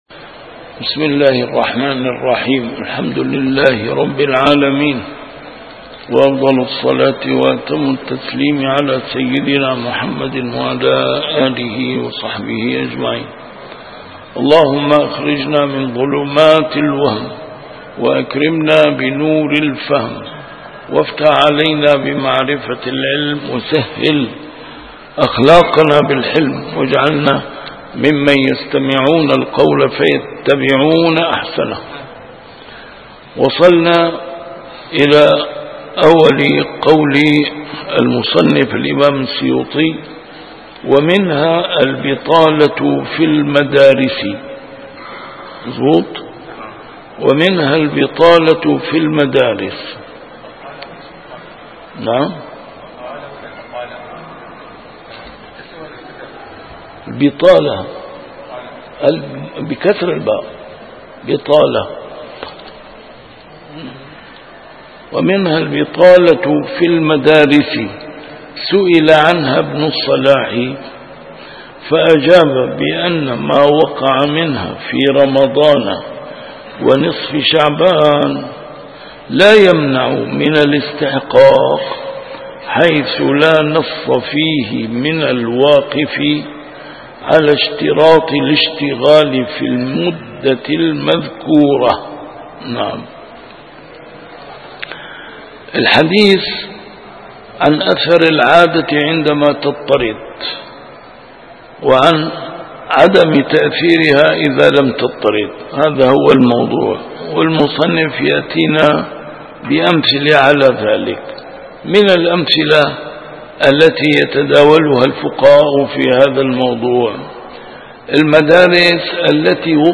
A MARTYR SCHOLAR: IMAM MUHAMMAD SAEED RAMADAN AL-BOUTI - الدروس العلمية - كتاب الأشباه والنظائر للإمام السيوطي - كتاب الأشباه والنظائر، الدرس الواحد والستون: العادة محكمة + في تعارض العرف مع الشرع